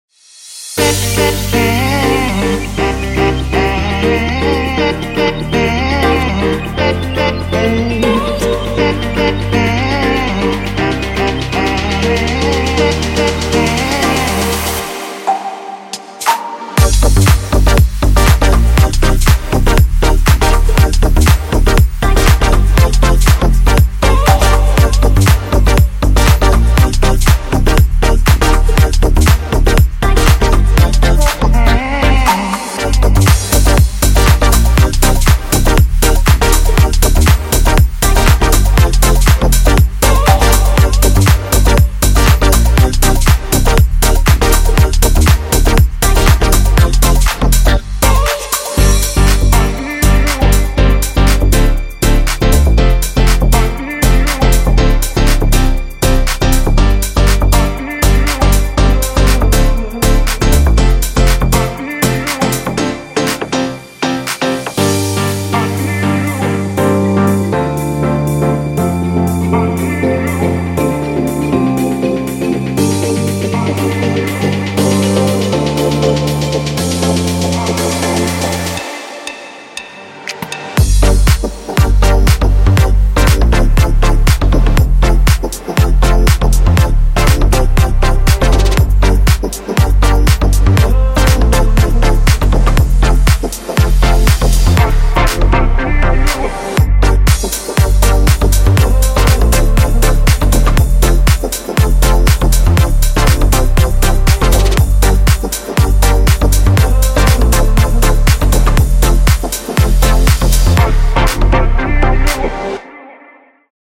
如果您正在寻找与Future Bass合成器和Housey节拍融合的吸引人的钩子，则此包适合您。